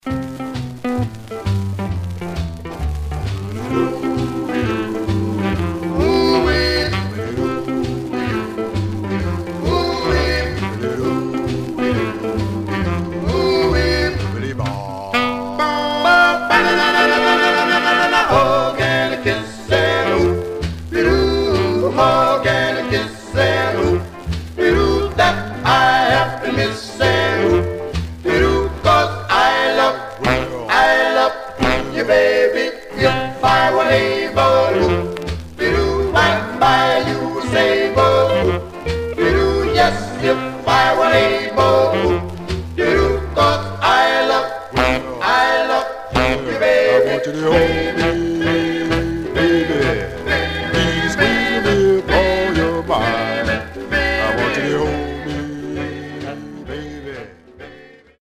Surface noise/wear
Mono
Male Black Group